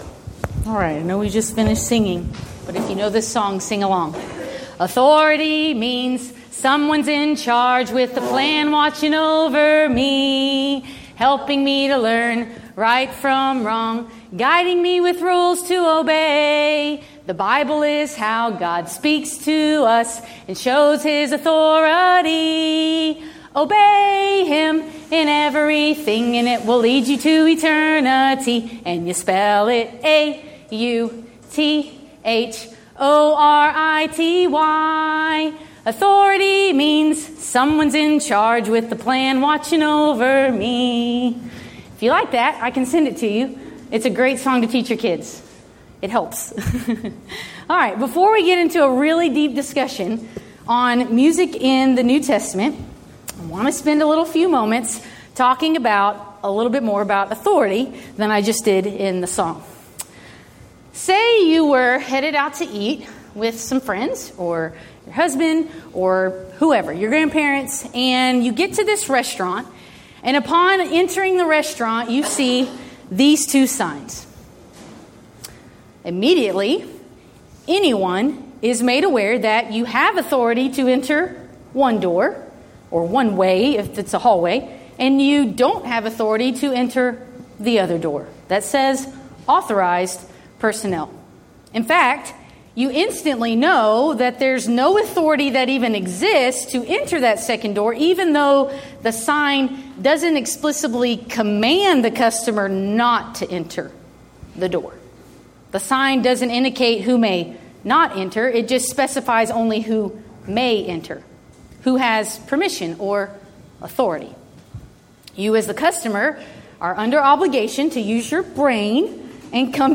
Event: 5th Annual Women of Valor Ladies Retreat
Ladies Sessions